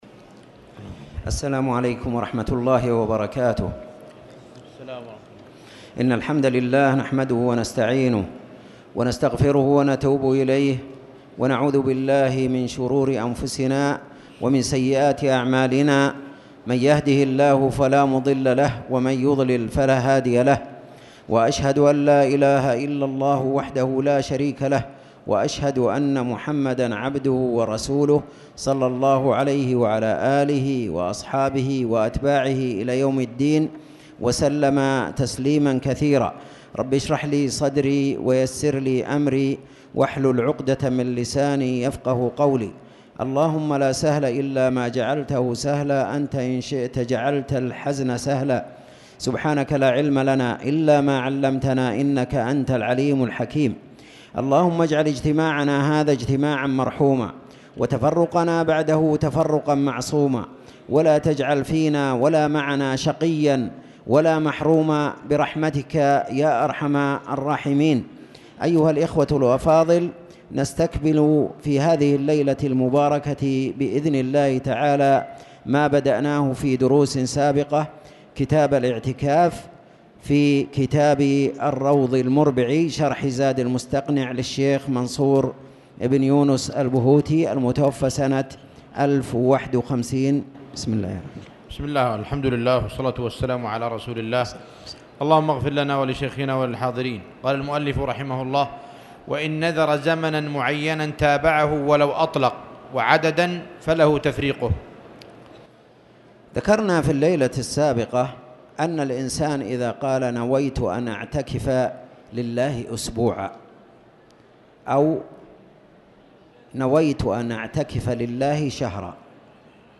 تاريخ النشر ٢٤ جمادى الأولى ١٤٣٨ هـ المكان: المسجد الحرام الشيخ